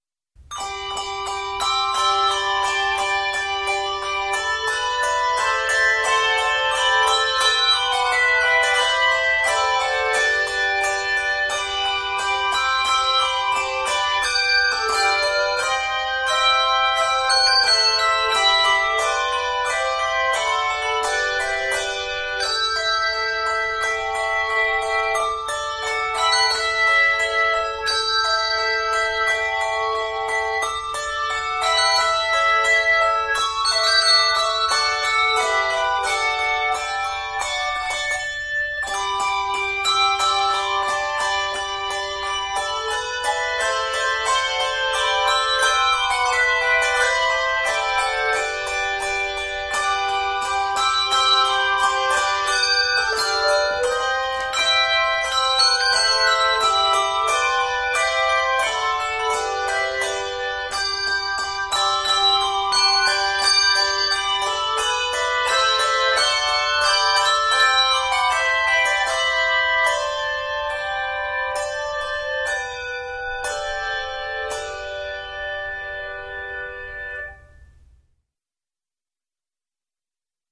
two octaves of handbells or handchimes